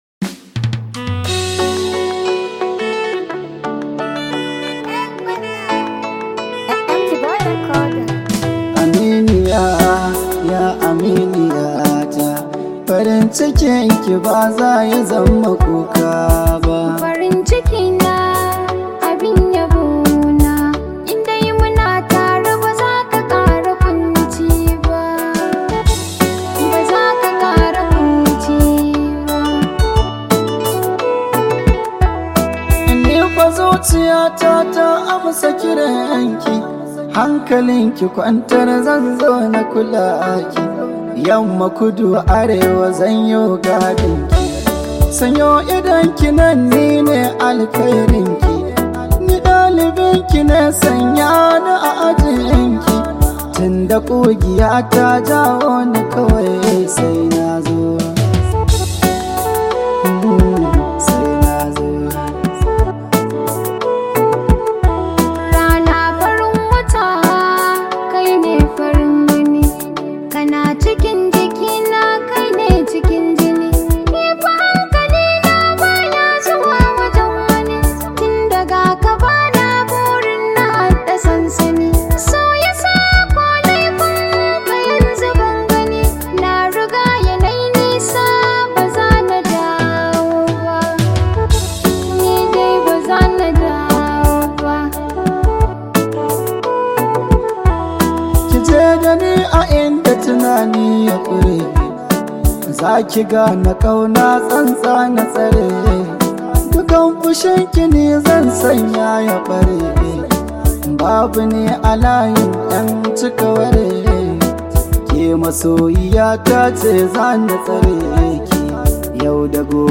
Category: Hausa Songs
a romantic song for lovers.
known for his unique “modern-hausa” music style